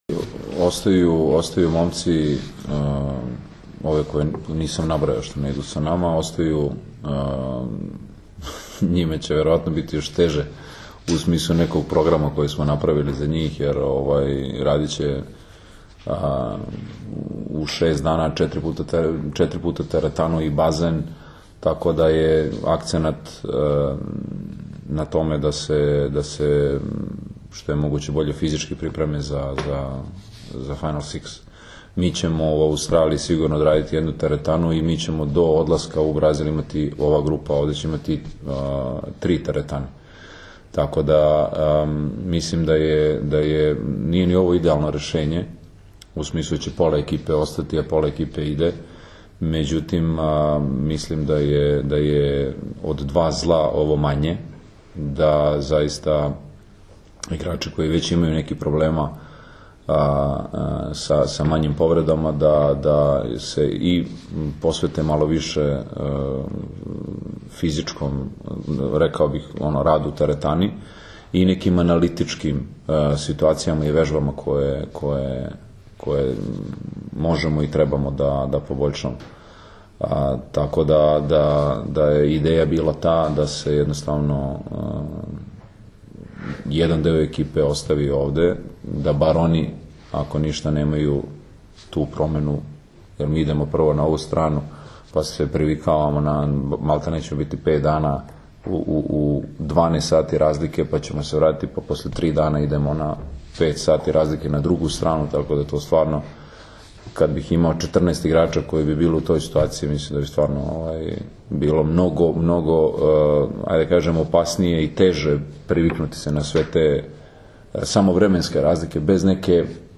IZJAVA NIKOLE GRBIĆA 2